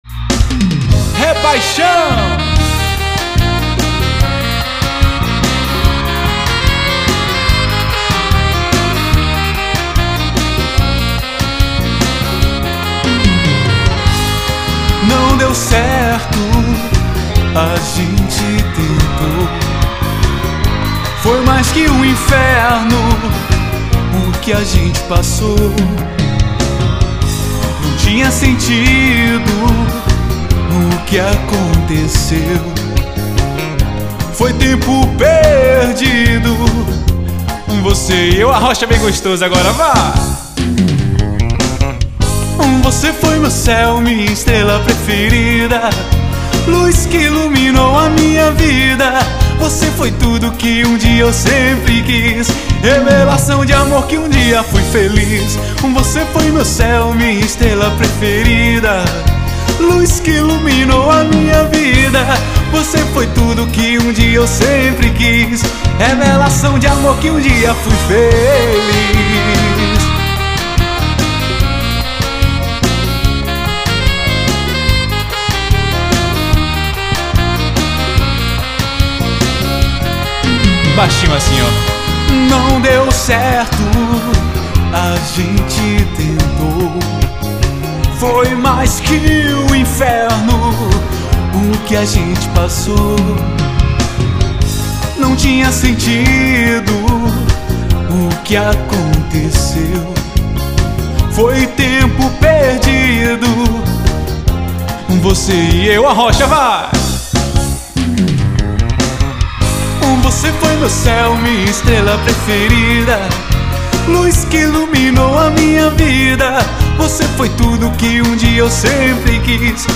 DVD ao vivo.